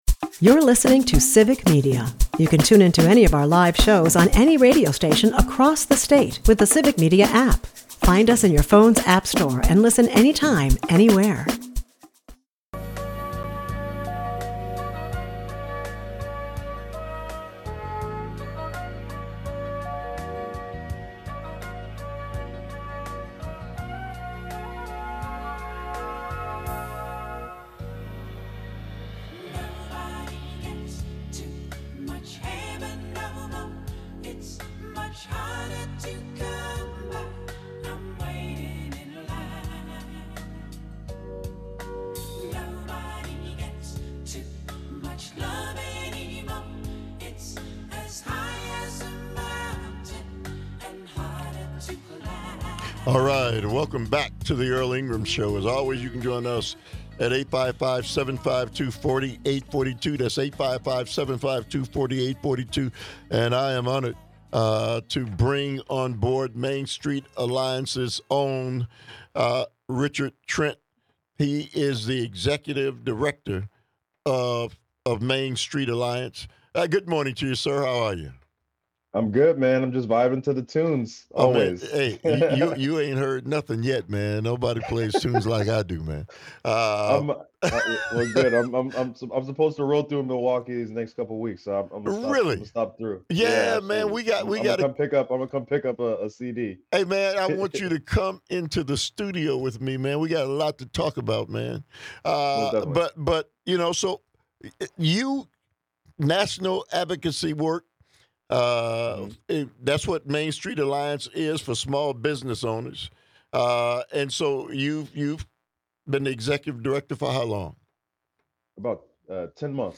Callers highlight that the failure rate in business stands at 90%, which discourages individuals from getting involved due to the high risks, high daycare cost and insufficient support for sustainability and growth.